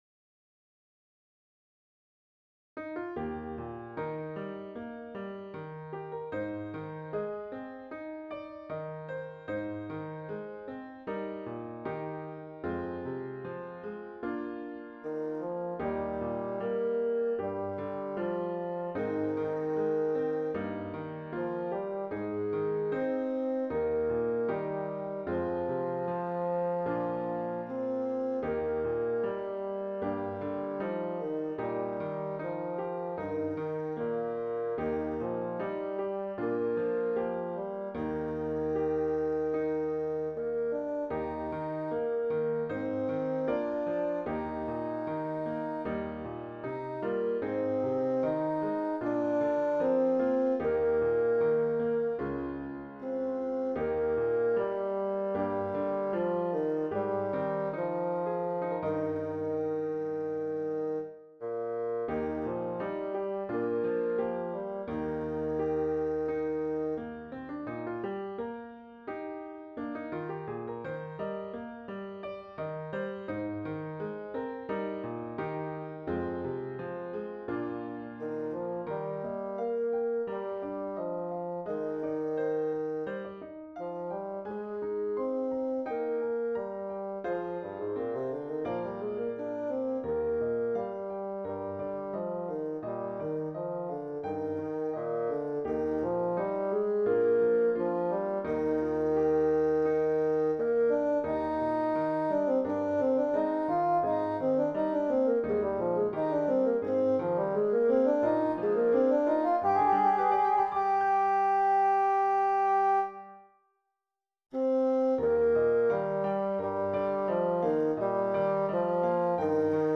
Intermediate Instrumental Solo with Piano Accompaniment.
Christian, Gospel, Sacred, Folk.
put to a flowing folk setting.